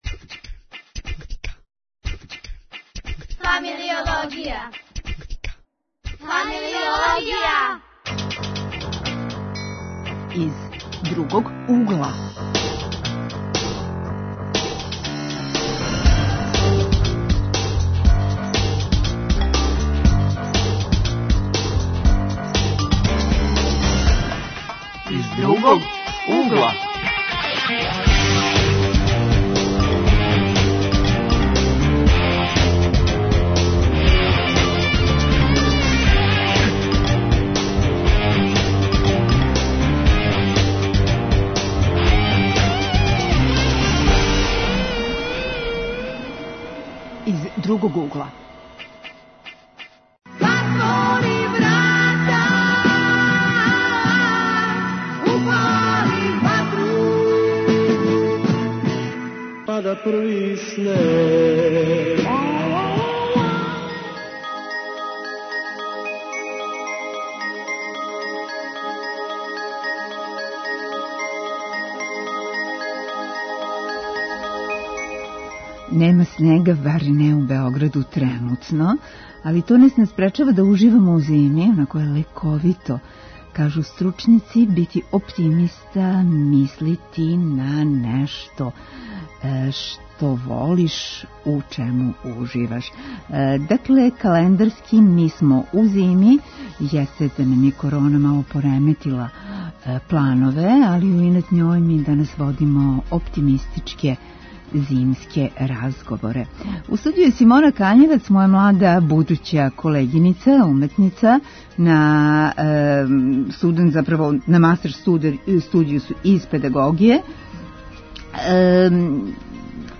Тема - зимски разговори: спортски, модни, еколошки, хедонистички, литерарно детективски!? Гости су млади који се баве зимским спортовима.